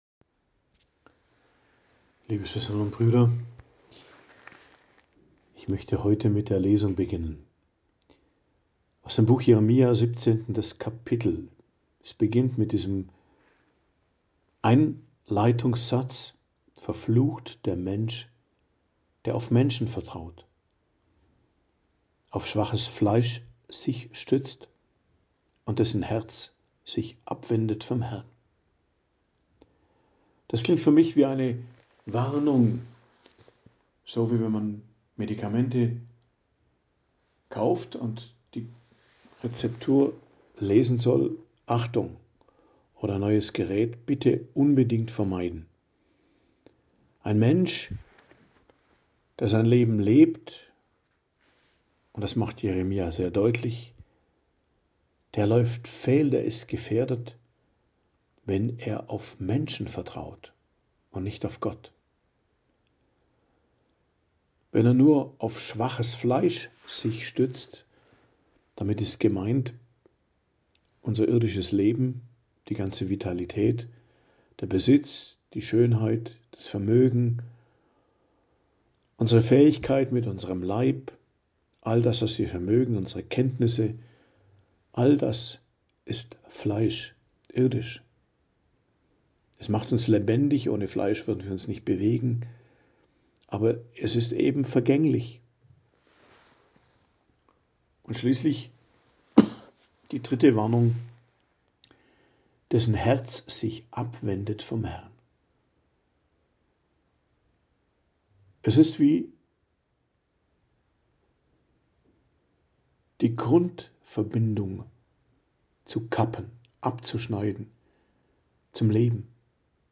Predigt am Donnerstag der 2. Woche der Fastenzeit, 20.03.2025